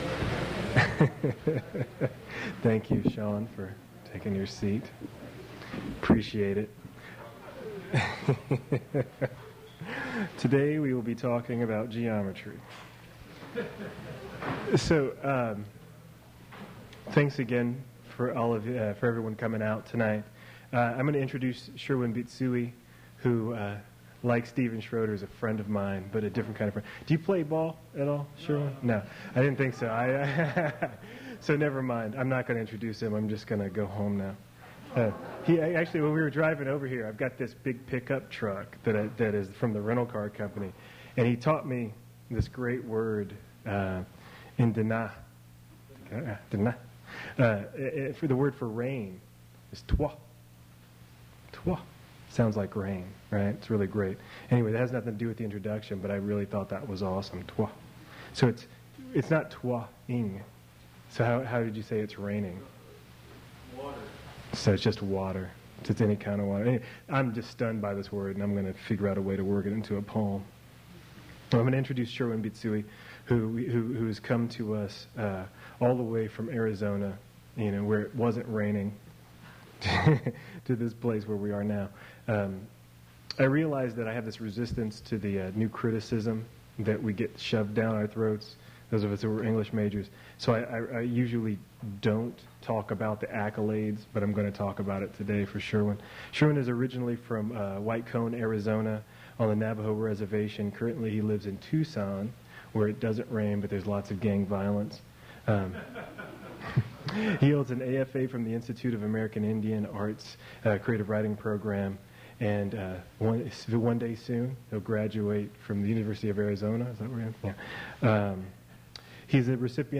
Poetry reading featuring Sherwin Bitsui
Attributes Attribute Name Values Description Sherwin Bitsui poetry reading at Duff's Restaurant.
mp3 edited access file was created from unedited access file which was sourced from preservation WAV file that was generated from original audio cassette.